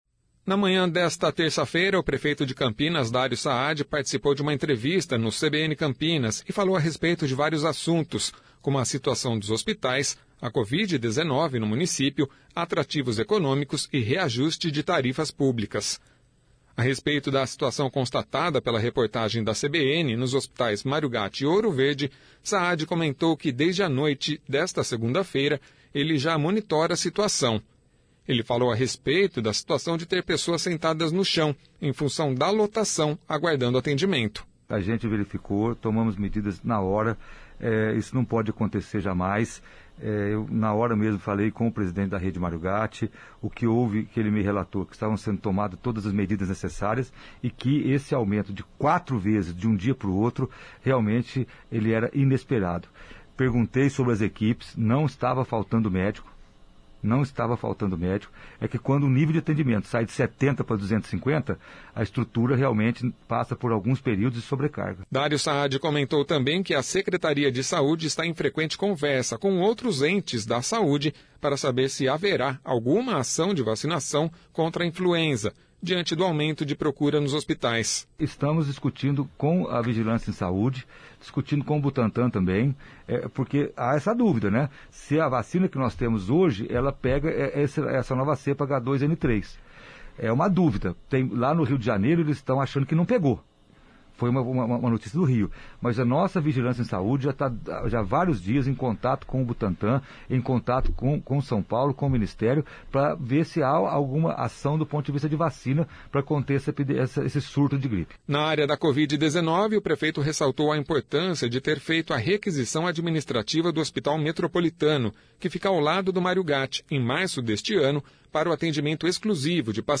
Nesta terça-feira o Prefeito de Campinas, Dário Saadi participou de uma entrevista no CBN Campinas e falou a respeito de vários assuntos como a situação dos hospitais, a covid-19 no município, atrativos econômicos e reajustes de tarifas públicas.